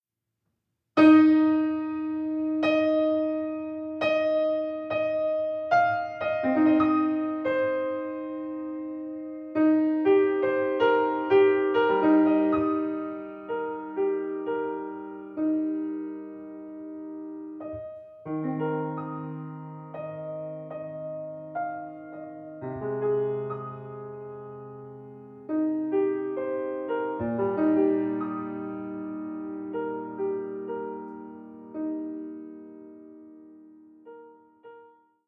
Voicing: French Horn